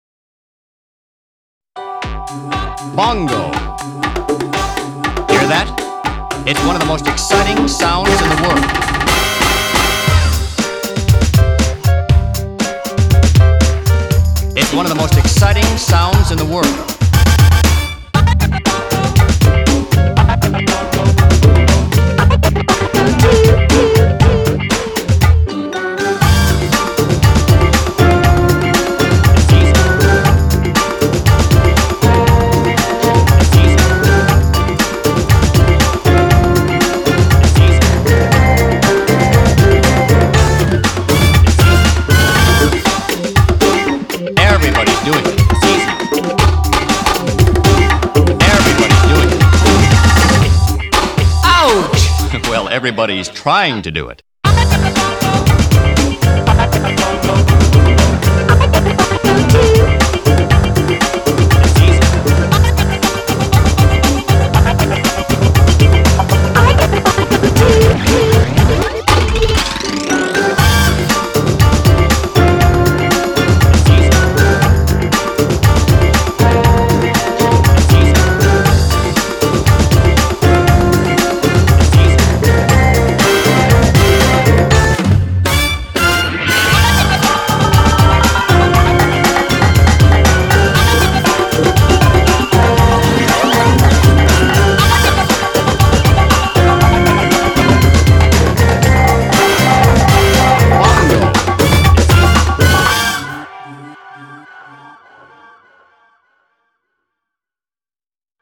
BPM119
Audio QualityMusic Cut